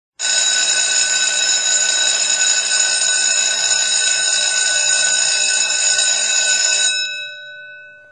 Звуки школьной перемены
Звук оповещающий об окончании урока